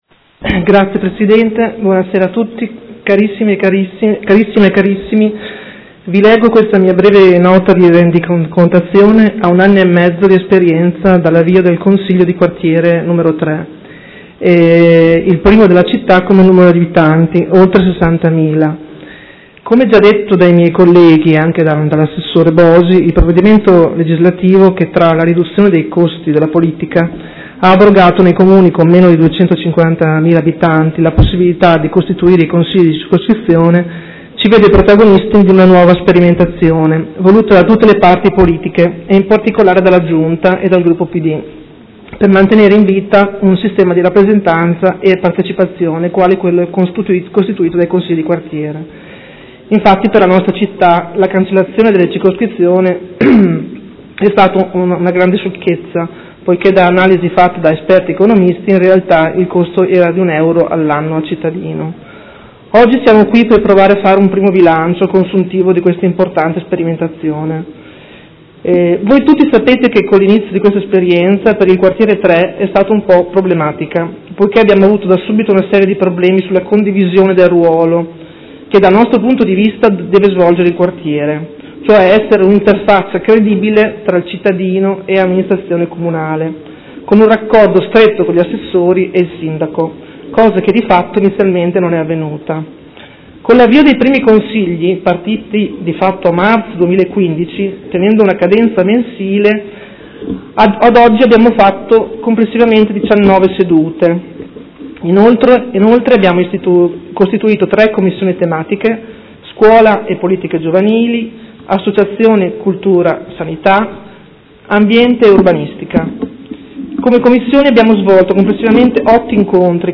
Seduta del 5/5/2016 Audizione dei Presidenti di Quartiere.